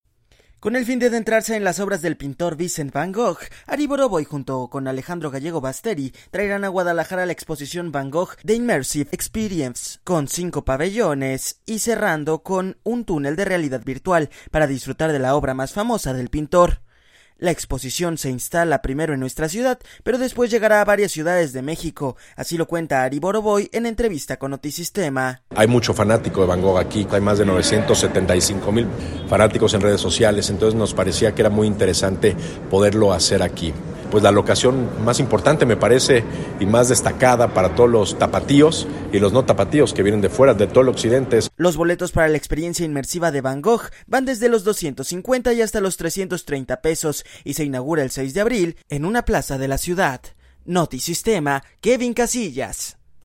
La exposición se instala primero en nuestra ciudad, pero después llegará a varias ciudades de México. Así lo cuenta Ari Borovoy en entrevista con Notisistema.